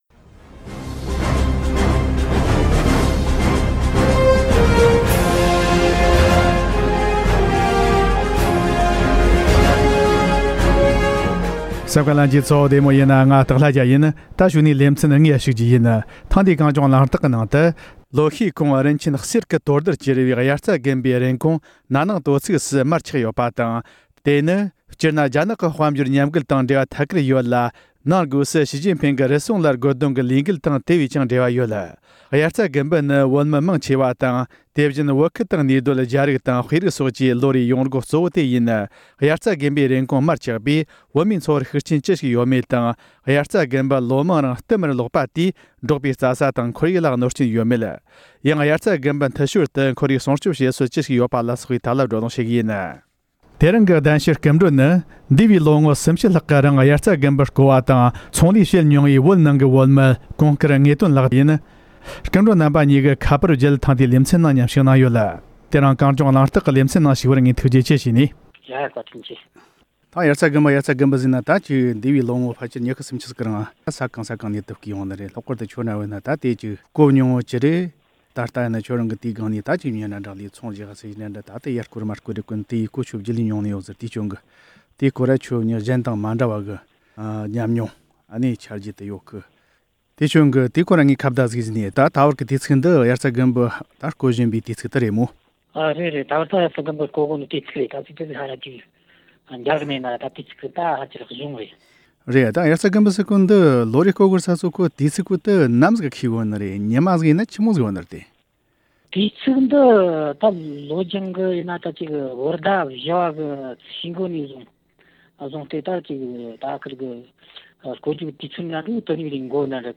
ཡང་དབྱར་རྩྭ་དགུན་འབུ་འཐུ་ཞོར་དུ་ཁོར་ཡུག་སྲུང་སྐྱོང་བྱེད་སྲོལ་ཅི་ཞིག་ཡོད་པ་ལ་སོགས་པའི་ཐད་བགྲོ་གླེང་བྱེད་རྒྱུ་ཡིན།